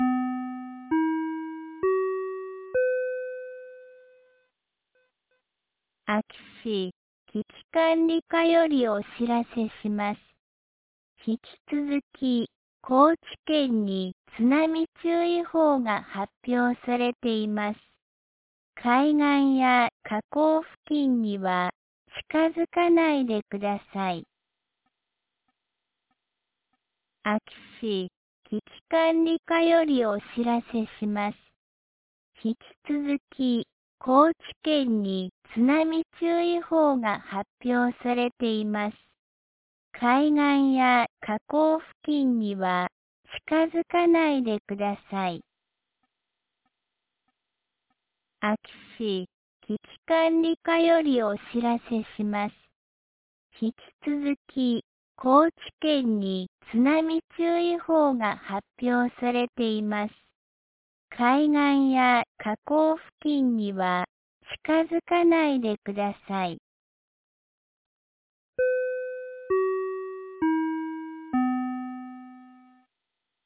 2025年07月30日 16時31分に、安芸市より全地区へ放送がありました。